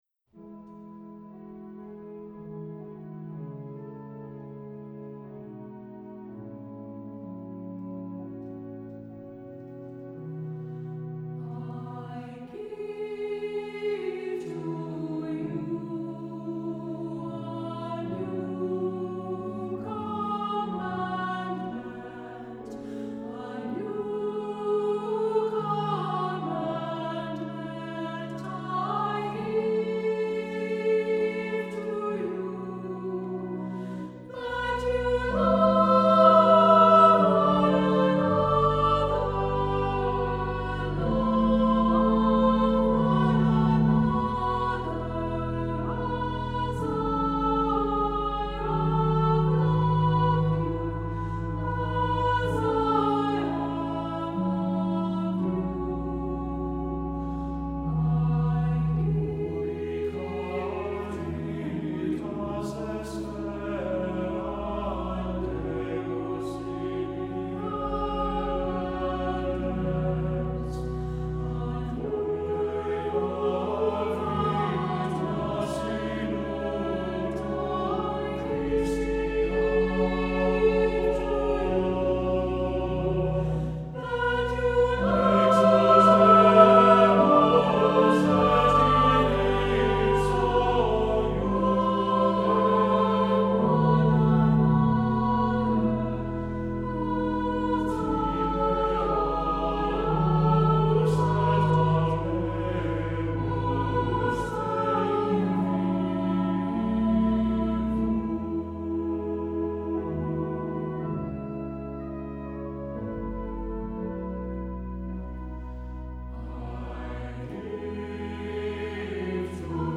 Accompaniment:      With Organ
Music Category:      Christian
A lyrical, modern tune for SA voices